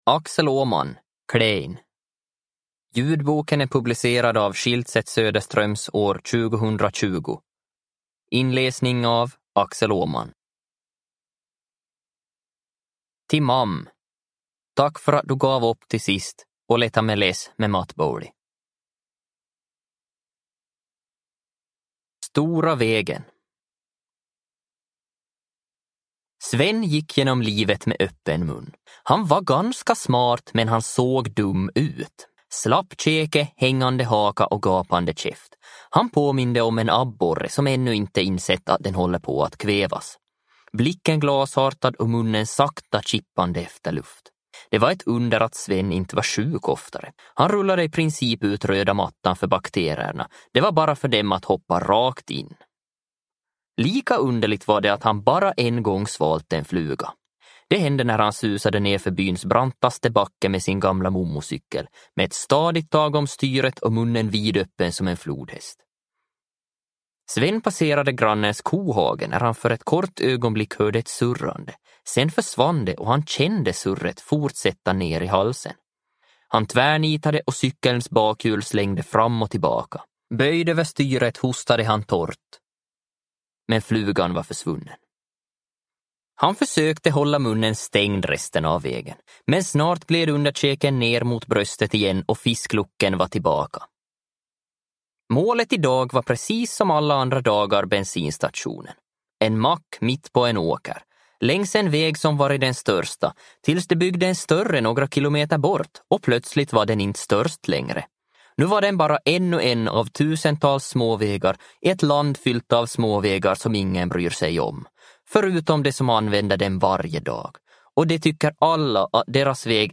Klein – Ljudbok – Laddas ner
Uppläsare: Axel Åhman